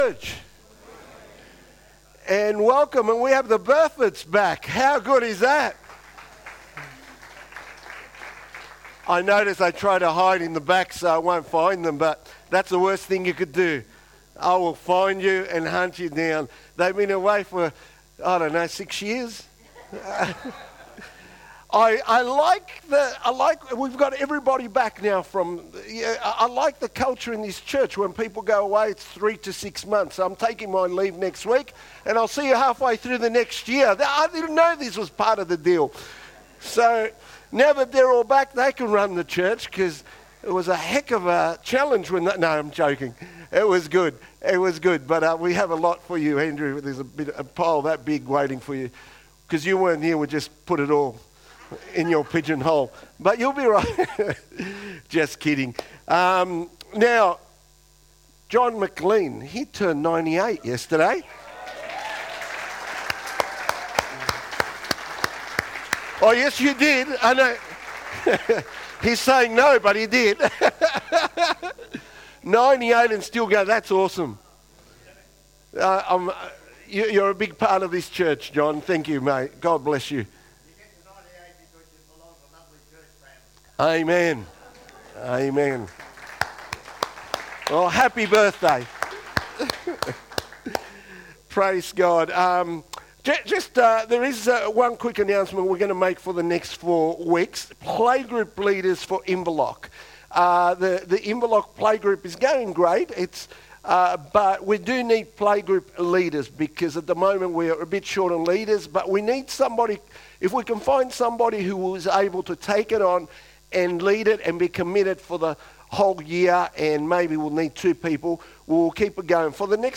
2024 • 47.64 MB Listen to Sermon Download this Sermon Download this Sermon To download this sermon